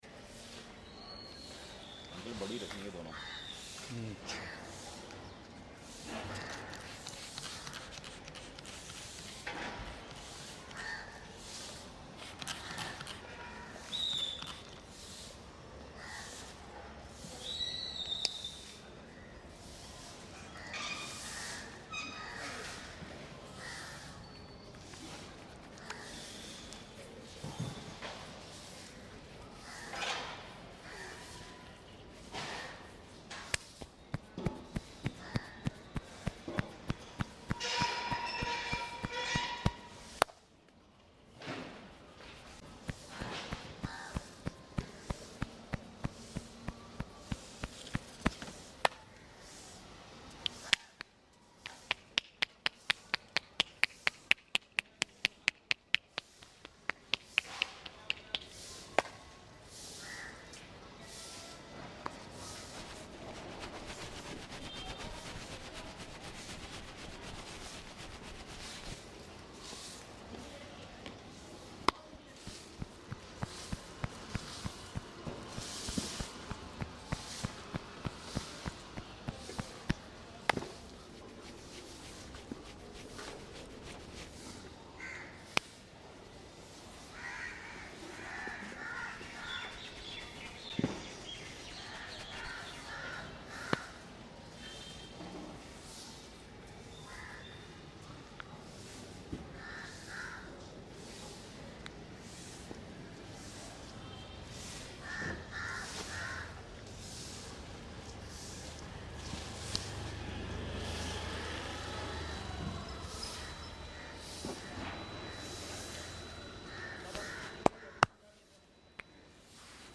Professional Barber Delivers Relaxing ASMR